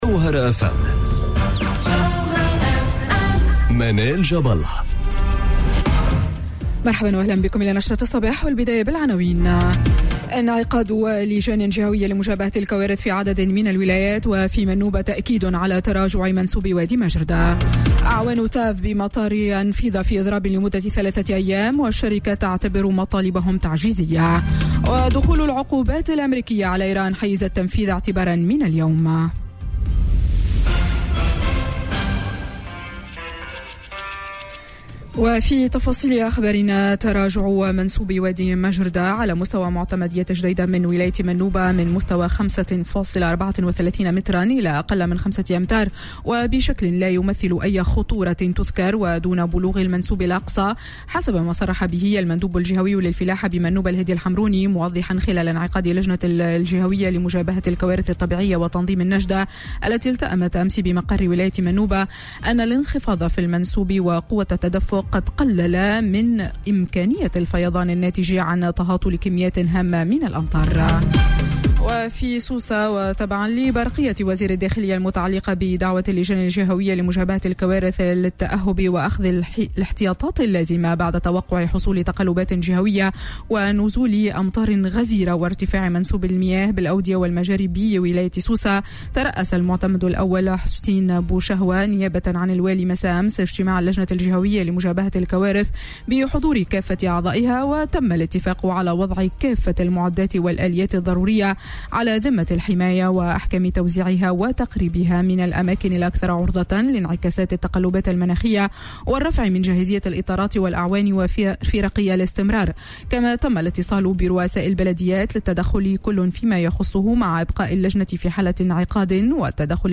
نشرة أخبار السابعة صباحا ليوم الثلاثاء 7 أوت 2018